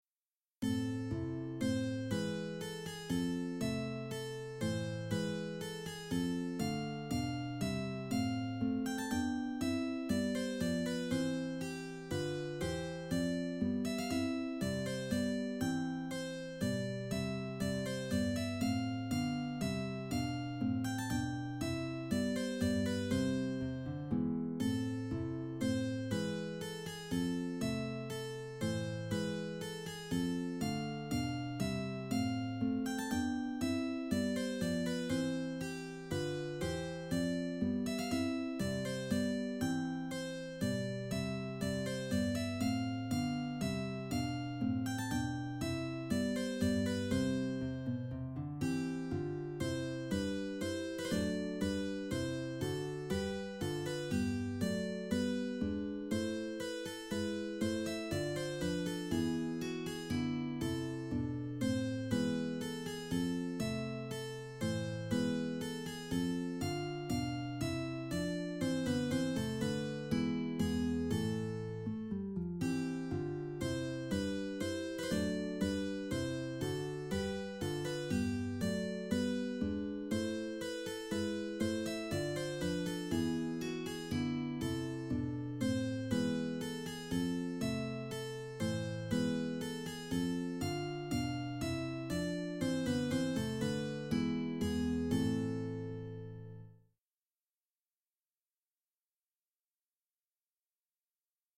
Here are nos. 1,3 & 15 arranged for mandolin and classcial guitar, along with computer-generated "performances" in mp3 format.